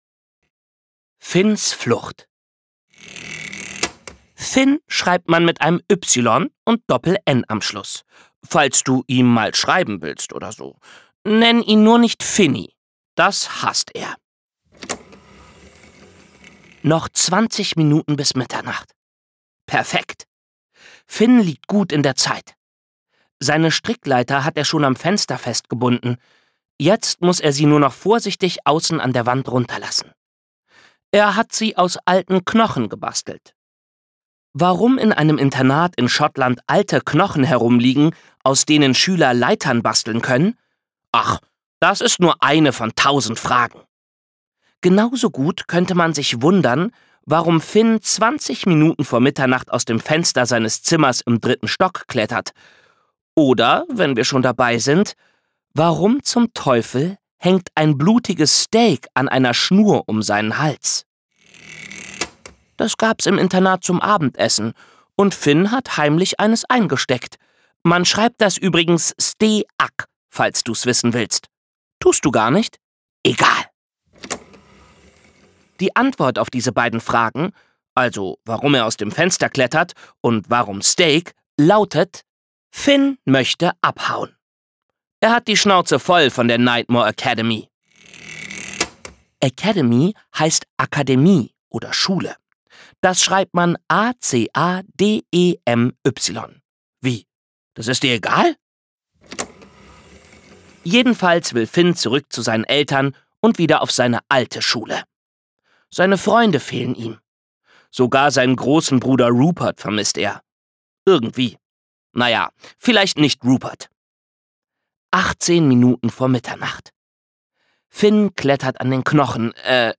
ungekürzte Lesung
Dazu gibt es auch noch Geräusche, die die einzelnen Szenen untermalen.
Unterschiedliche Stimmen für die einzelnen Figuren und er bringt auch die jeweilige Stimmung gut rüber. Zusammen mit den Geräuschen kann man das Geschehen schon beinahe vor sich sehen.